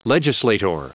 Prononciation du mot legislator en anglais (fichier audio)
Prononciation du mot : legislator